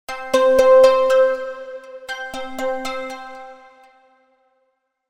We chose C Melodic Minor.
2. Added a synth track (we choose Zebra 2), selected a nice patch, and double clicked in the phrases area to open the track editor.
The Velocirapture tool makes this quick, and even provides some velocity sculpting to make it a little more interesting.